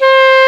SOFT SAX 6.wav